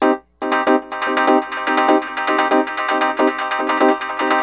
Dub Clang.wav